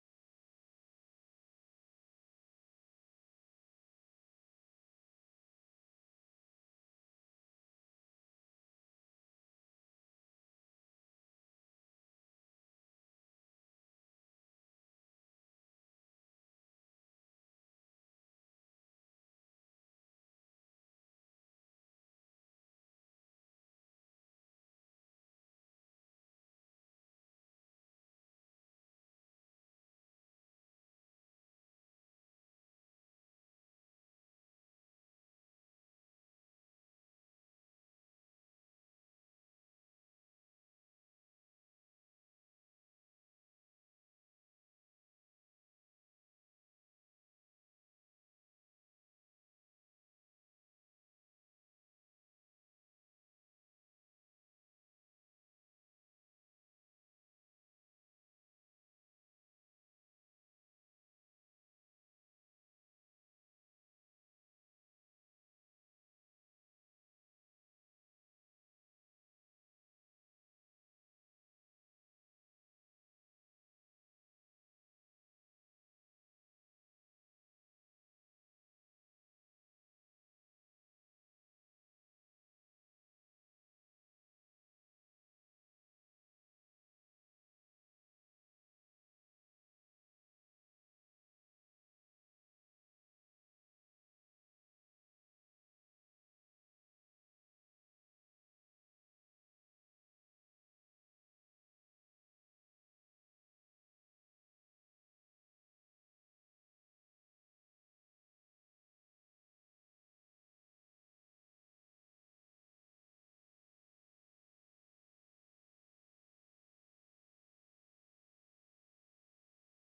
خطبة - كفى بالموت واعظا